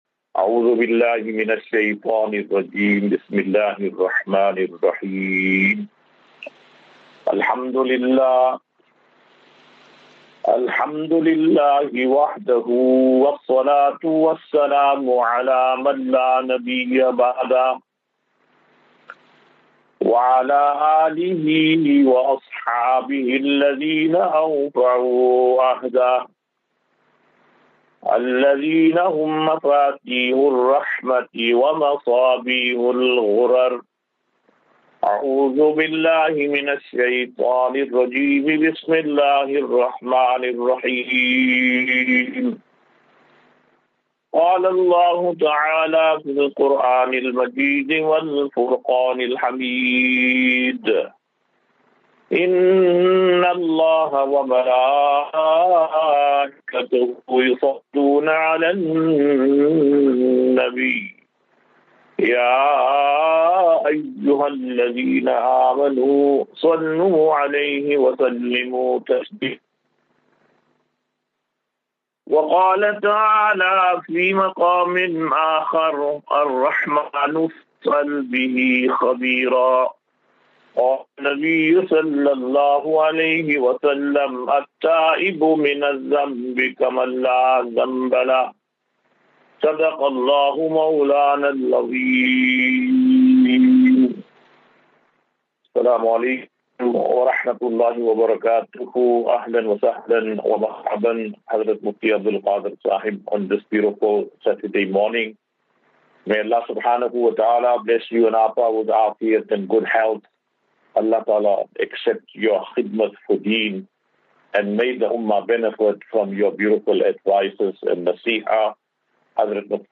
QnA.